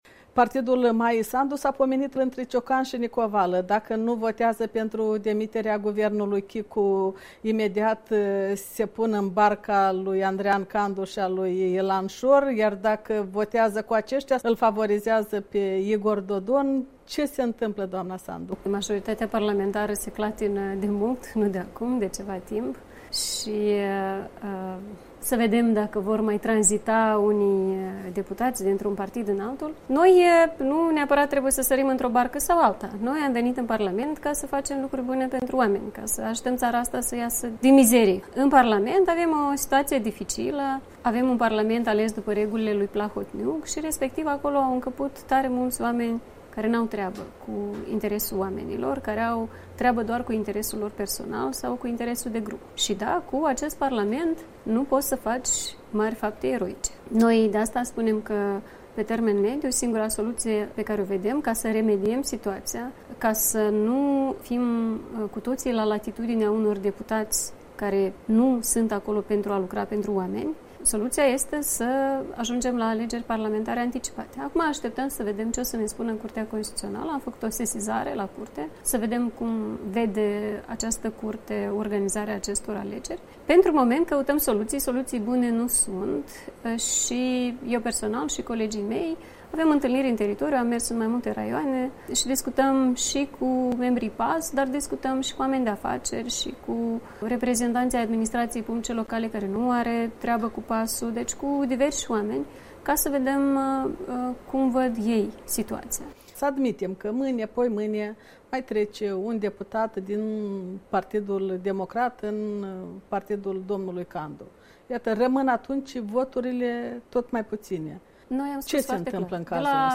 Interviu cu lidera PAS, Maia Sandu despre parlamentul suspendat și guvernul Chicu minoritar